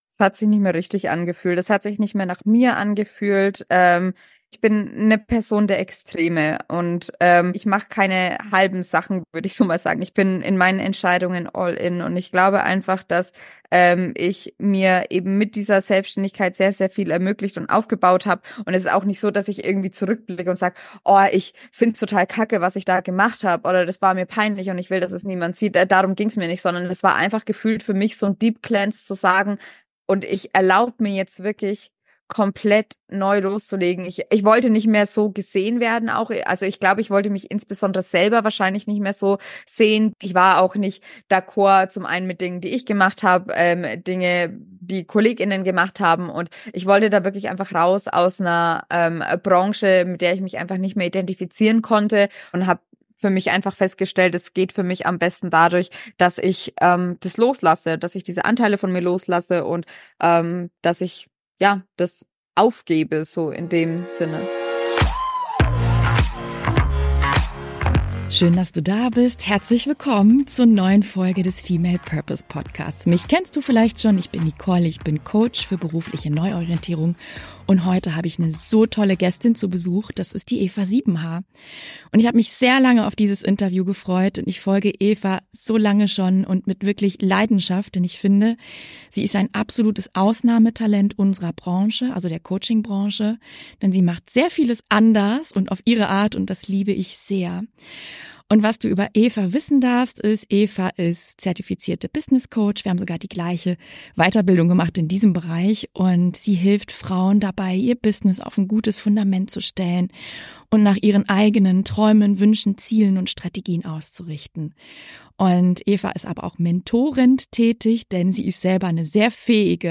Darum geht's in diesem super offenen Interview!